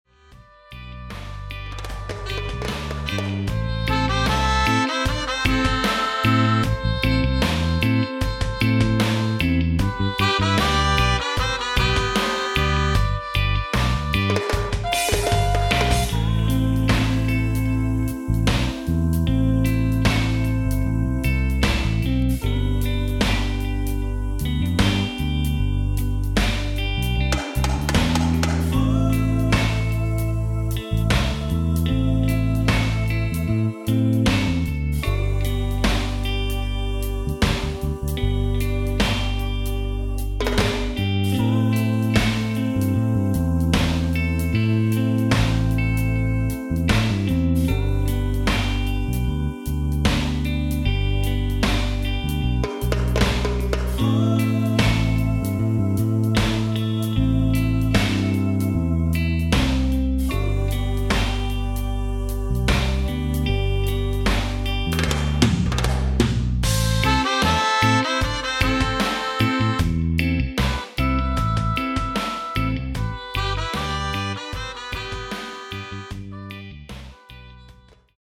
Singing Club Sing Along Songs